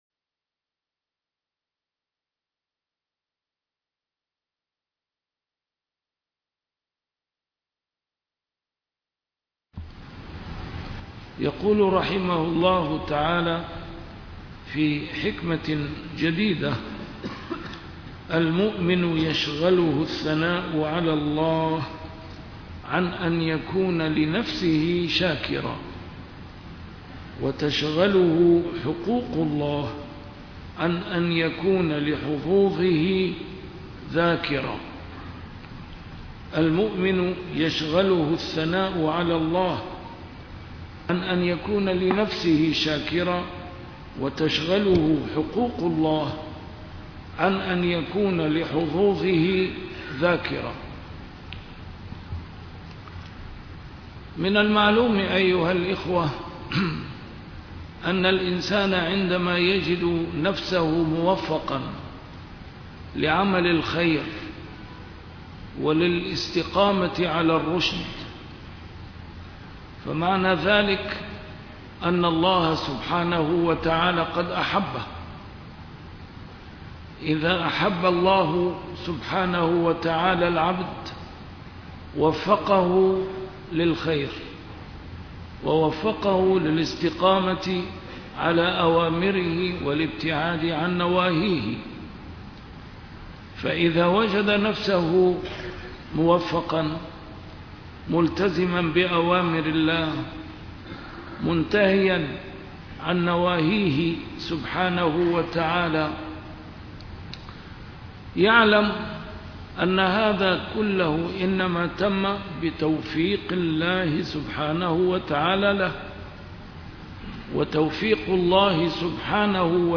A MARTYR SCHOLAR: IMAM MUHAMMAD SAEED RAMADAN AL-BOUTI - الدروس العلمية - شرح الحكم العطائية - الدرس رقم 263 شرح الحكمة رقم 240 - 241